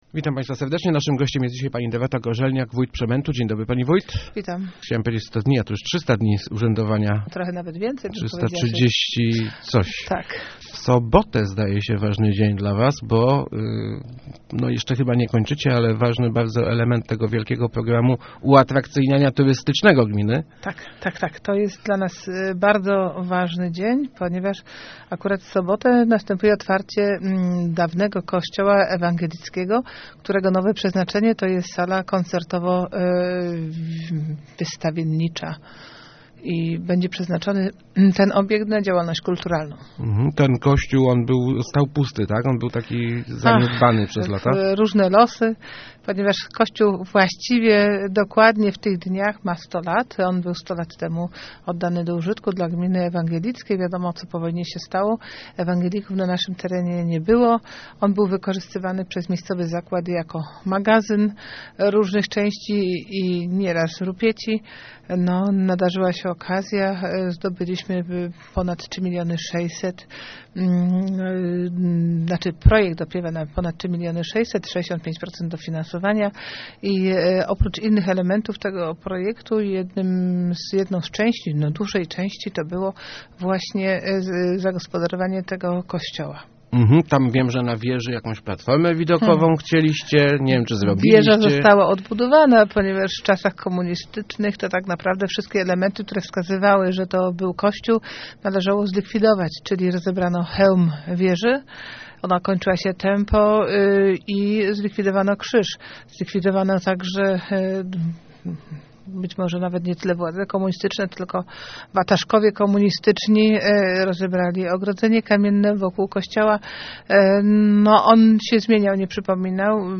W sobotę oficjalnie oddany do użytku zostanie gmach dawnego zboru ewangelickkiego w Kaszczorze. Będzie pełnił funkcję sali wystawienniczo-koncertowej - mówiła w Rozmowach Elki wójt gminy Przemęt Dorota Gorzelniak.